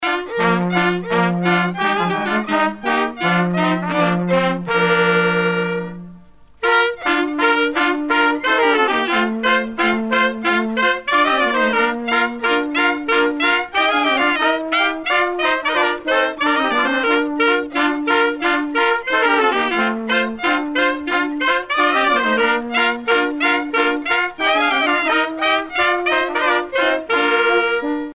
danse : gigue
groupe folklorique
Pièce musicale éditée